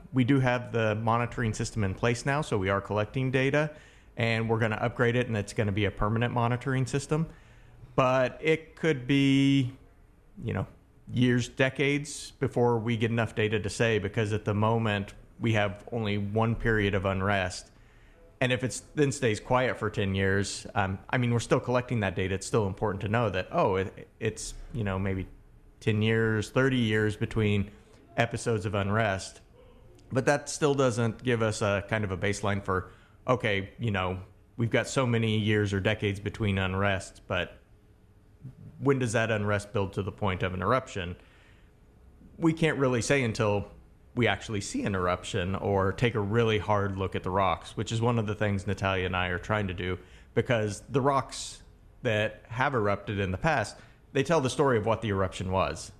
Two scientists from the U.S. Geological Survey are here to help strengthen partners with local agencies to assist in their research of the Manu’a volcano and also maintain the monitoring network they set up last year for data collection.